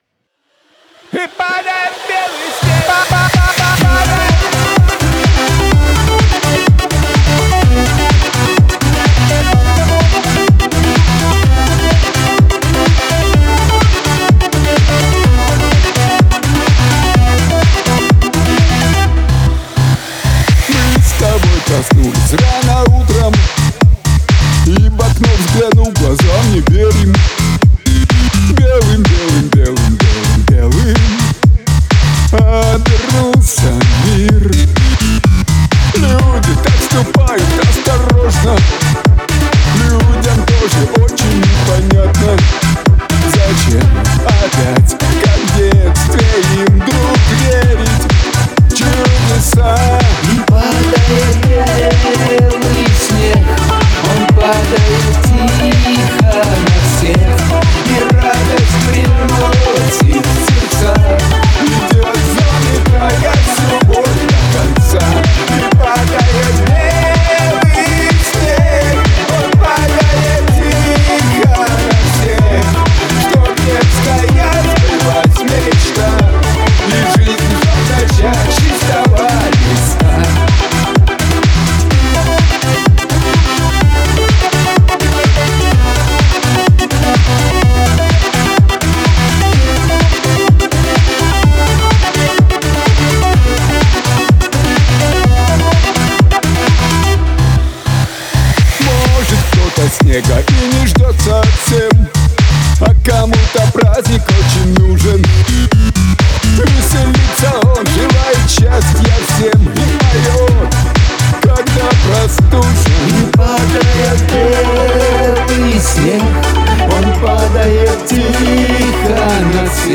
ХайХет заменил на более короткий и пробивной, сразу стал слышен снер, я его даже прибрал.
Лид немного поднял в середине. Полностью перепел голос, стало аккуратней, меньше соплей.
Но голос звучит задушено, я его сильно подавил, а иначе он не ложится, или уши у меня устали и я уже не пойму.